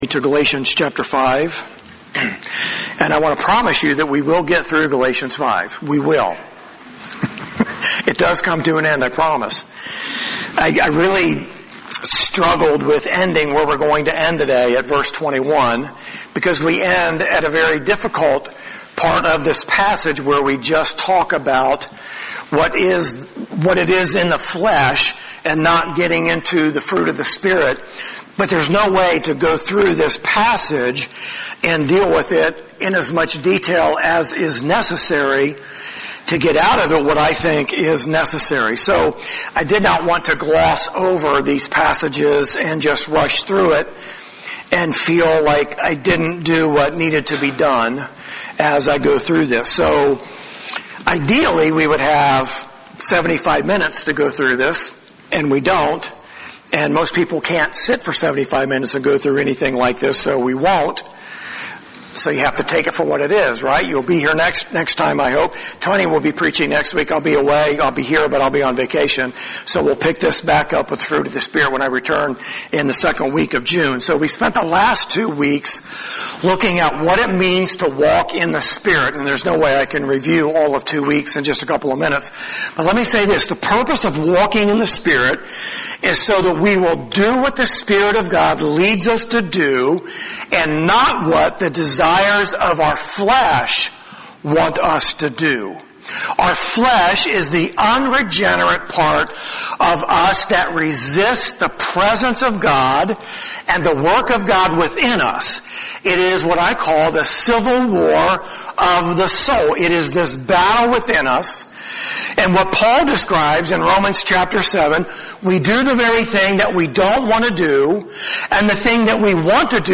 Grace Fellowship Church Unionville, PA Audio Sermons